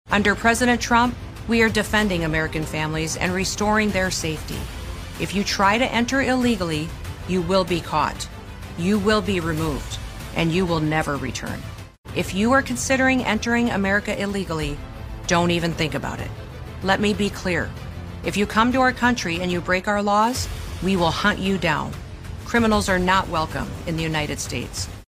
THE DEPARTMENT OF HOMELAND SECURITY IS LAUNCHING A NEW AD CAMPAIGN AS PART OF THEIR EFFORT TO COMBAT ILLEGAL IMMIGRATION. THE LATEST AD FEATURING D-H-S SECRETARY KRISTI NOEM ISSUES A WARNING TO POTENTIAL ILLEGAL ALIENS FROM OTHER COUNTRIES … TELLING THEM NOT ENTER THE U-S ILLEGALLY, OR THEY WILL BE REMOVED…